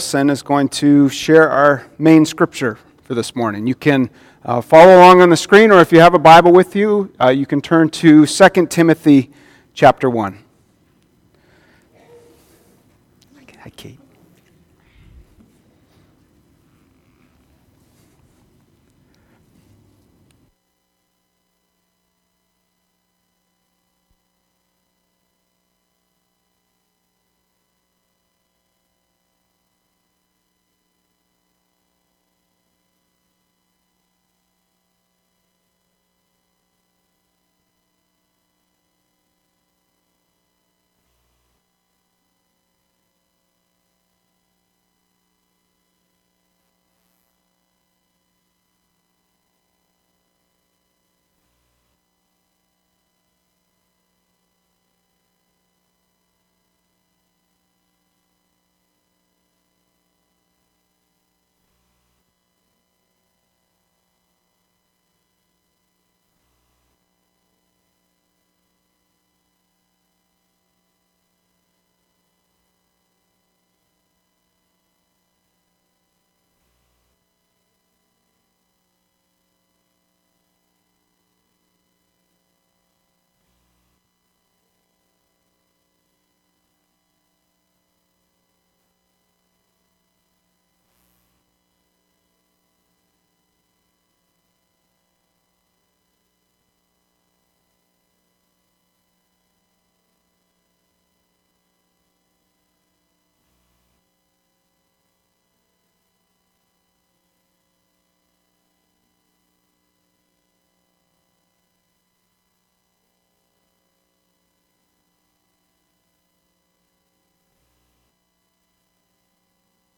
Sunday Service by Cornerstone UMC - Lake Crystal